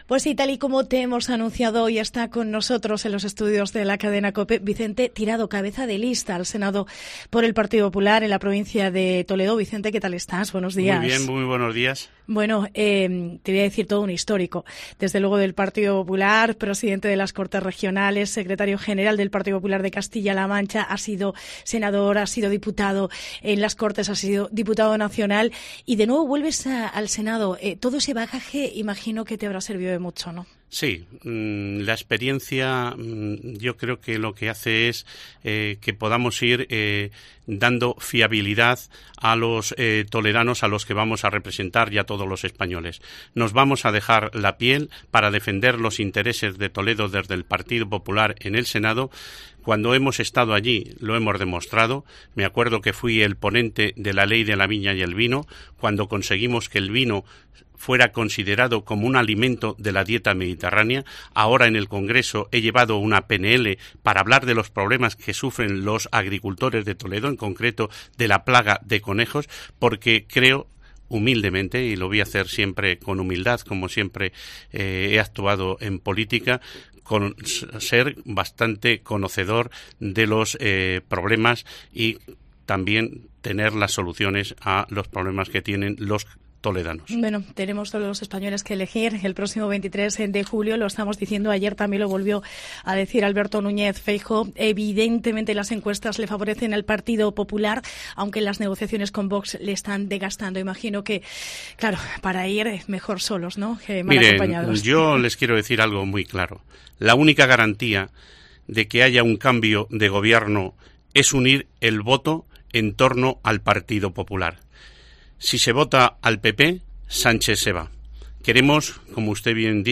Entrevista a Vicente Tirado